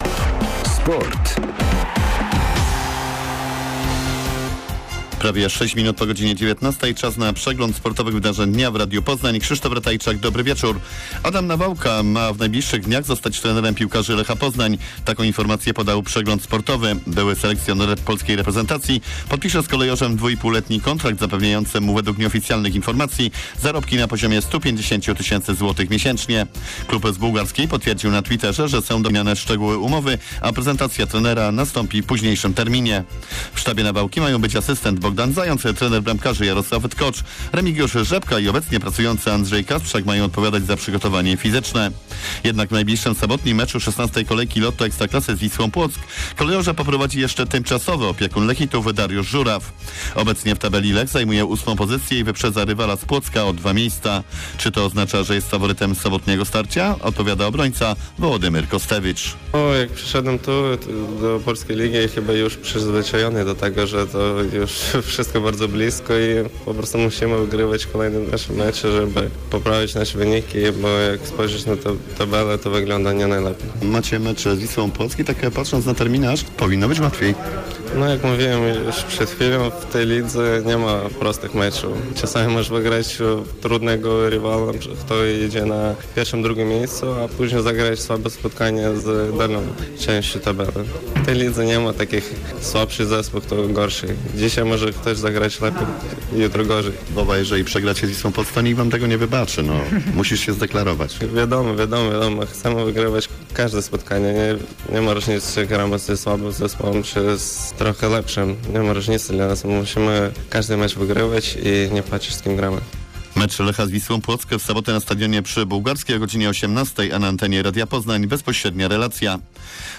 22.11. serwis sportowy godz. 19:05